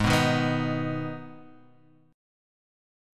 G#m chord